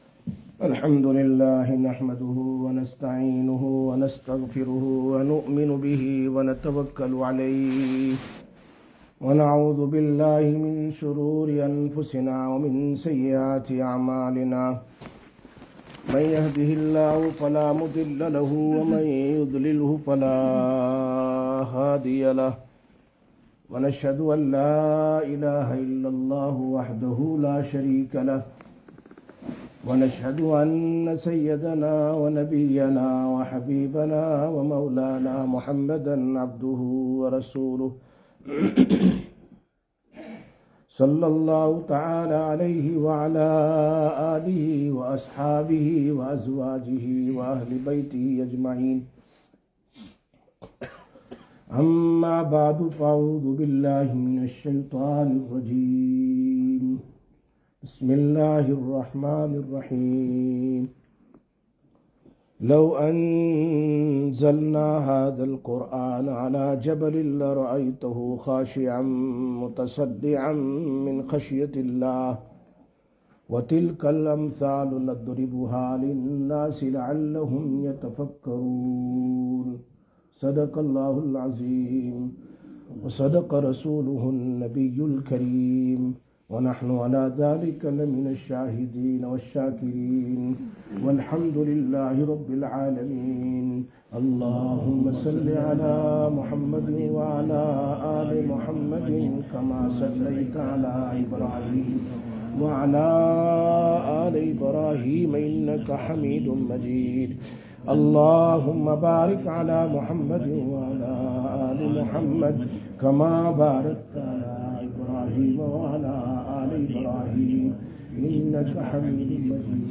07/07/2023 Jumma Bayan, Masjid Quba